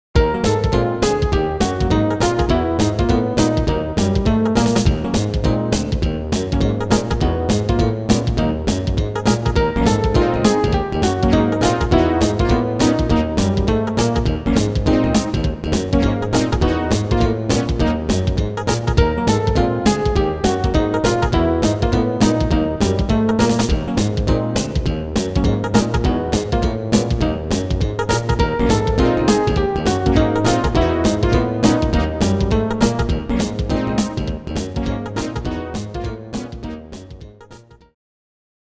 as recorded directly from the Roland MT-32 Sound Module